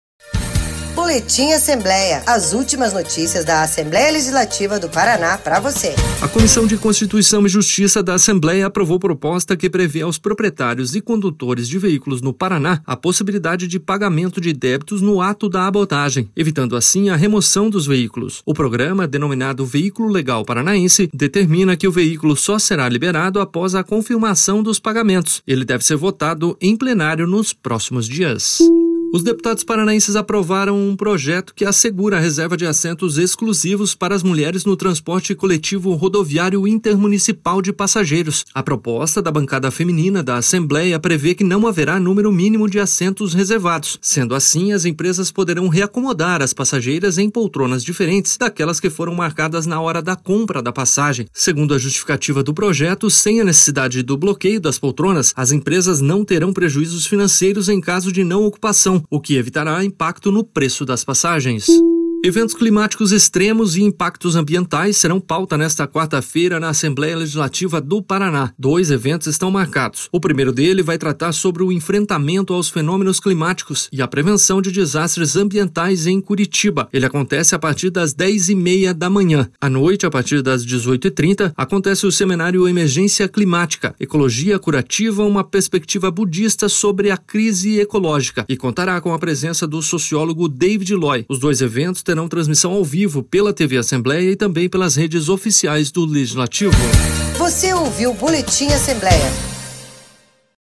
Confira em áudio um resumo das principais notícias desta terça-feira, 21 de maio, no Boletim Assembleia.